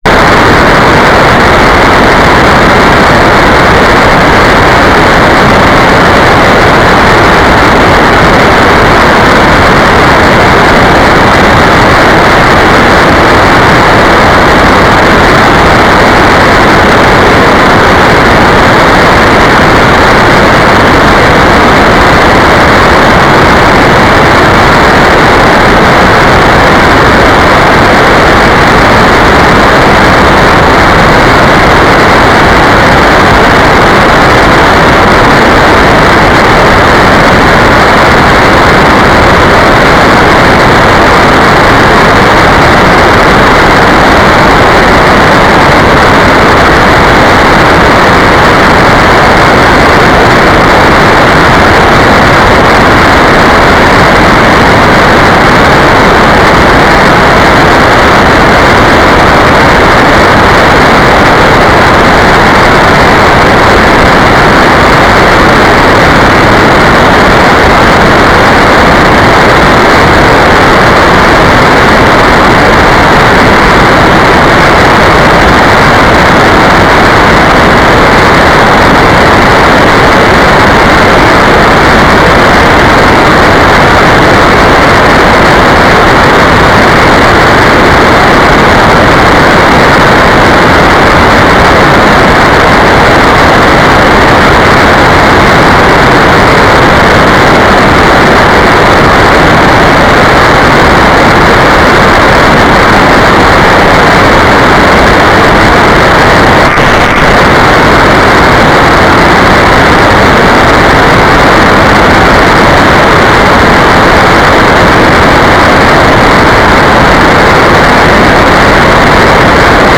"transmitter_description": "Modu U - GMSK2k4 - Transmitter",
"transmitter_mode": "GMSK",